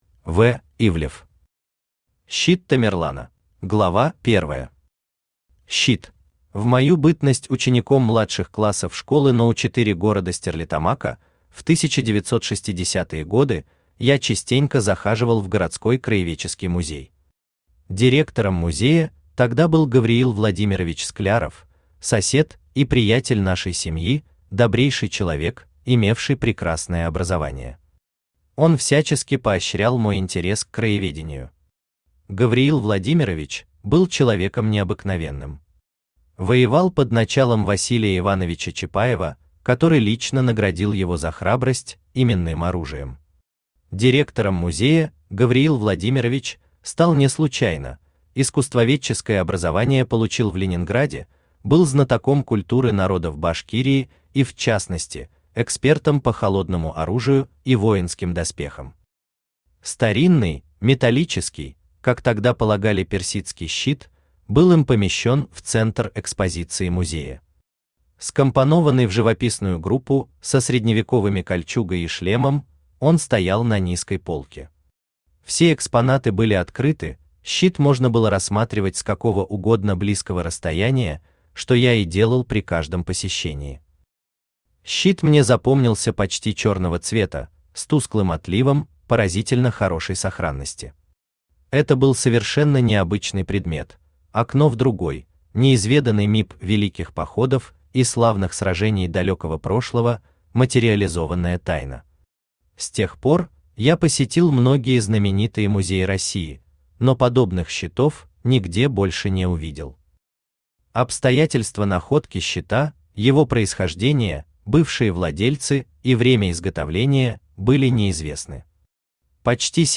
Аудиокнига Щит Тамерлана | Библиотека аудиокниг
Aудиокнига Щит Тамерлана Автор В. В. Ивлев Читает аудиокнигу Авточтец ЛитРес.